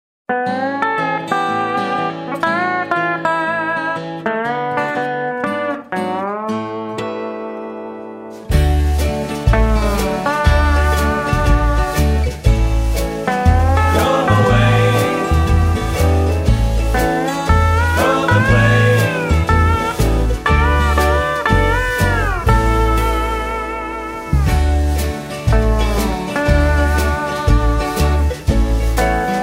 Instrumental Tracks.